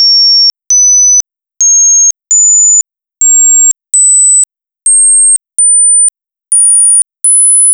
Listen to 5,500-10,000hz tones in increments of 5,00hz and pauses for 1,000hz intervals: